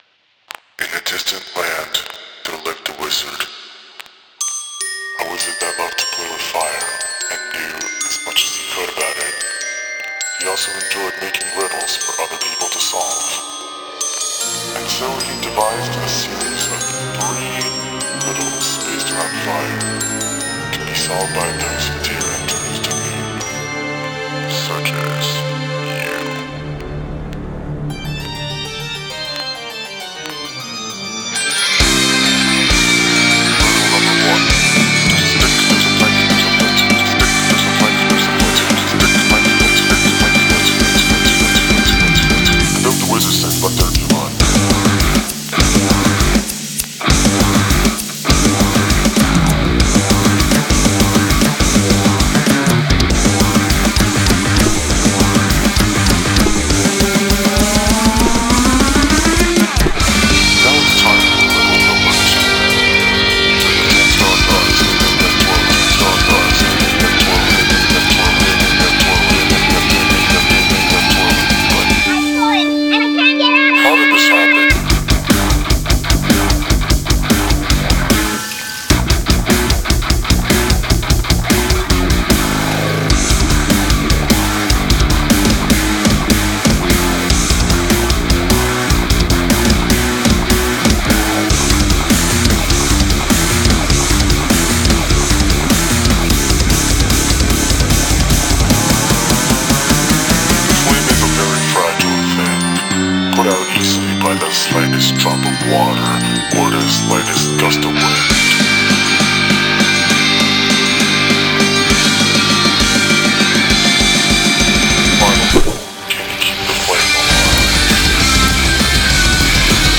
BPM200
Audio QualityPerfect (High Quality)
Have you never heard of 4/3 time signature?